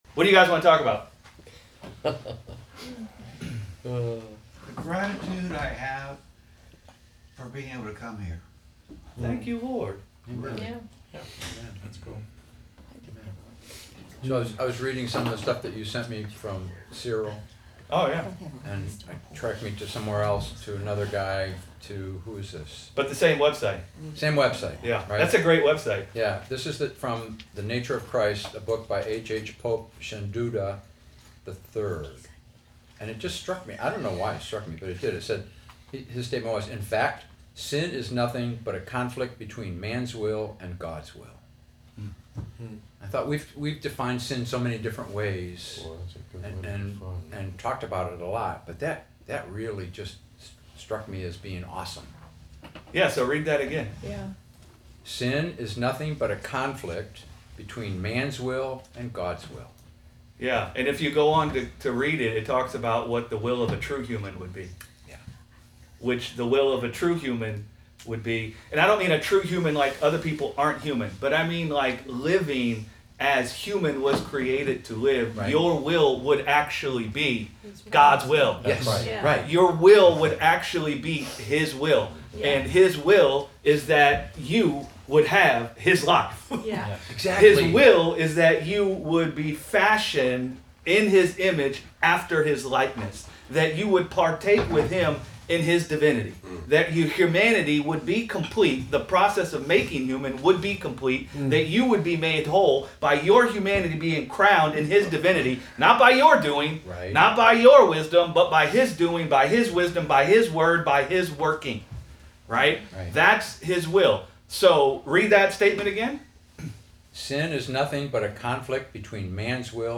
Sunday Bible Study: The Will of God and the Will of Man - Gospel Revolution Church